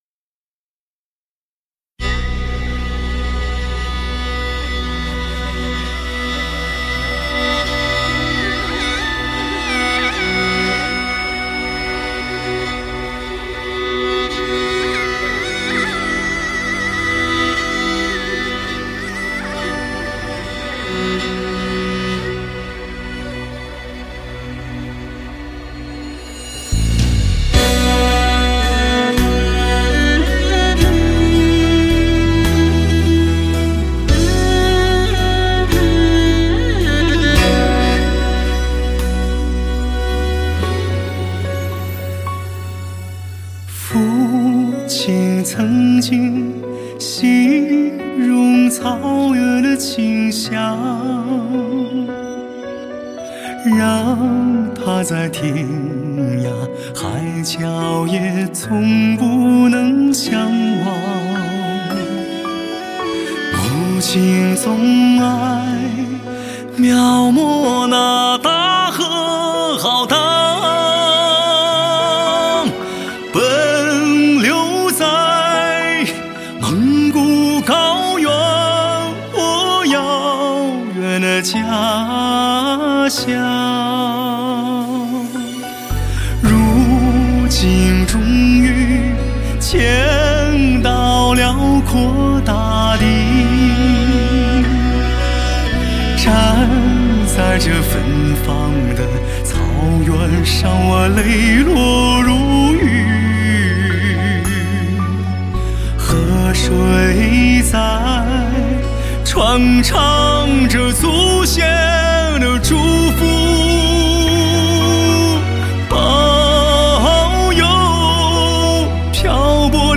最具Hi-Fi震撼的发烧男声，一旦拥有，别无所求；此声一起，发烧无极